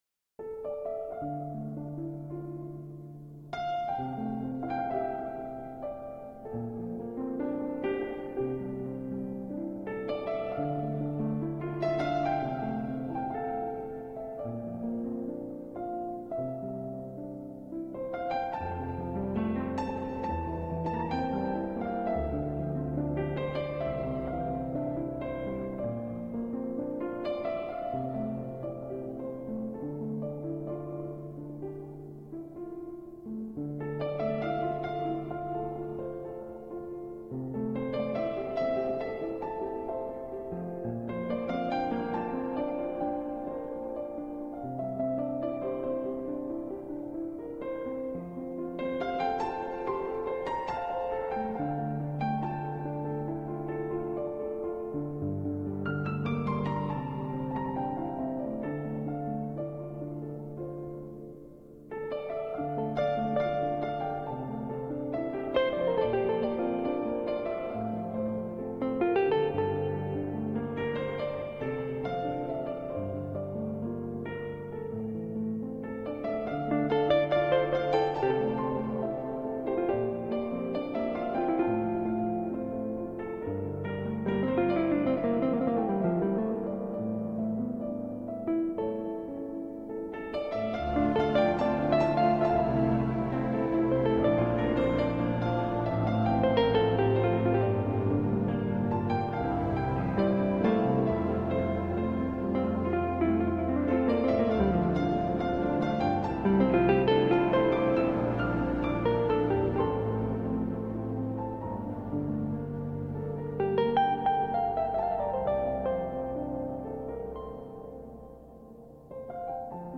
New age romantic piano music.